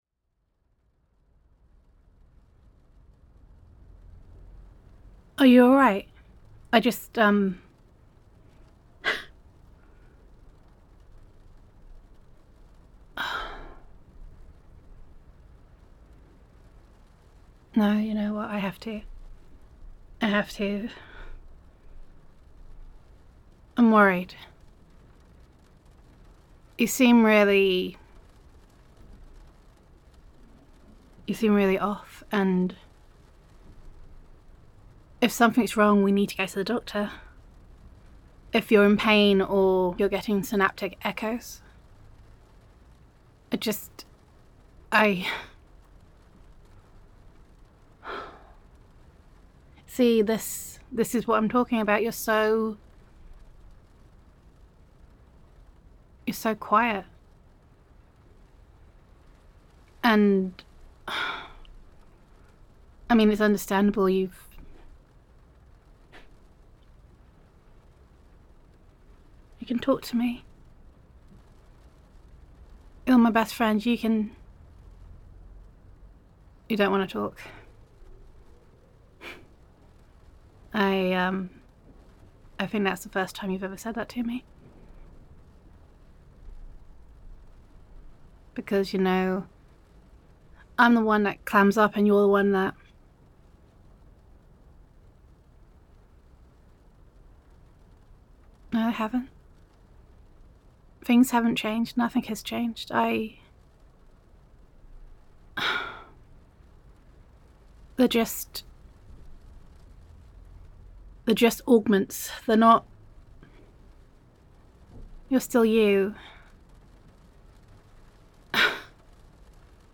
[F4A] I Only See You [Love Confession][Friends to Lovers][Science Fiction][Augmentation][Cyberpunk][First Kiss][Concern][Gender Neutral][Your Best Friend Is Worried About You After You Being Heavily Augmented After an Accident]